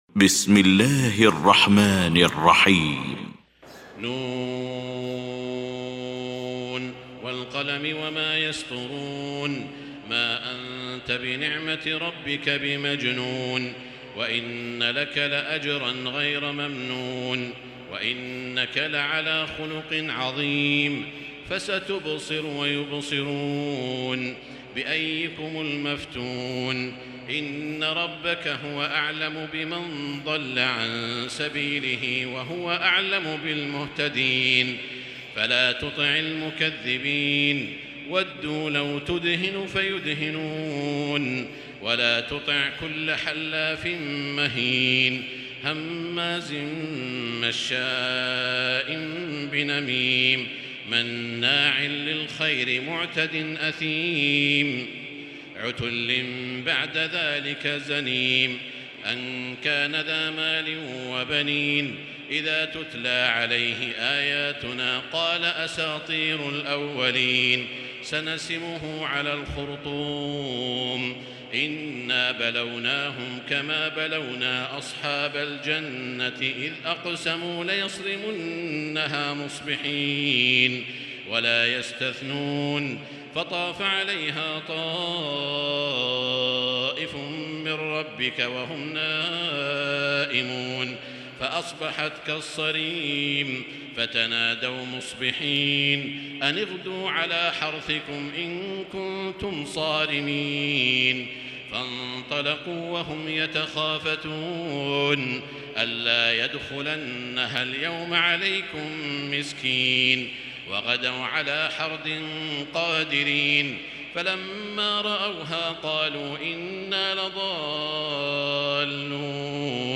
المكان: المسجد الحرام الشيخ: سعود الشريم سعود الشريم القلم